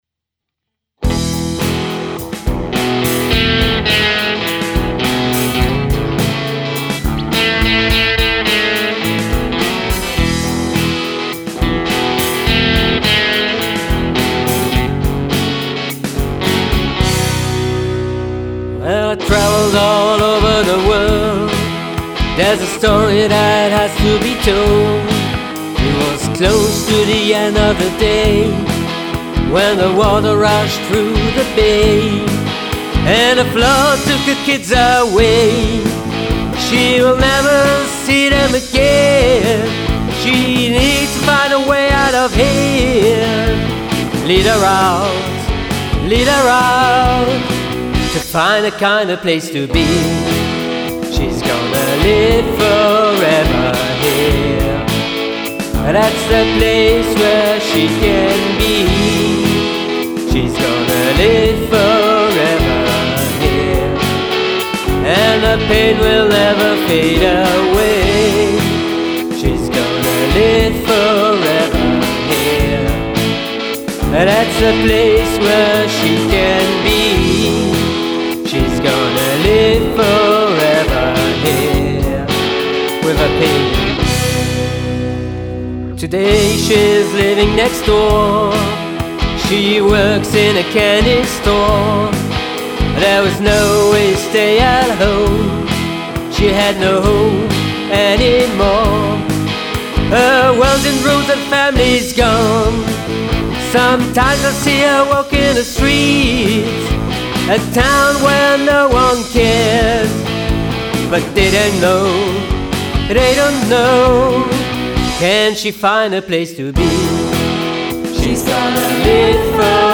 The songs were written and recorded under a tight time schedule, so these are just workshop recordings.